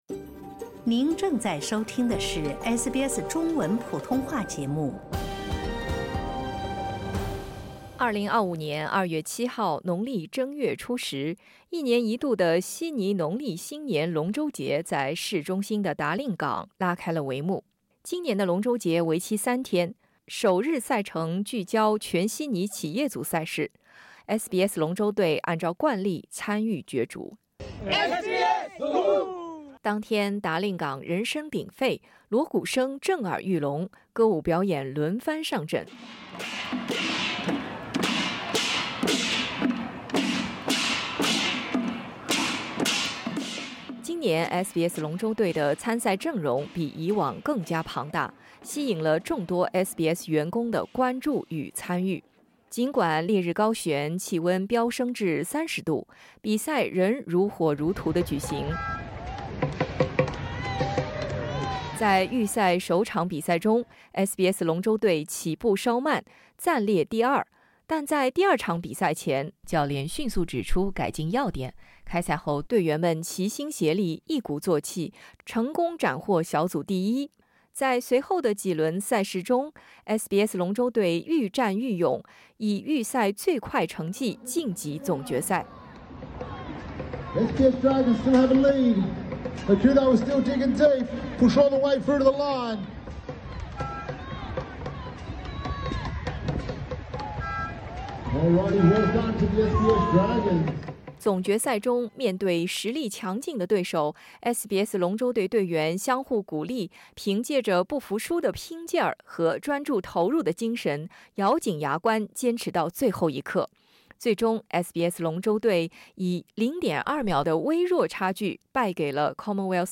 赛后，我对几位关键人物进行了深入的采访。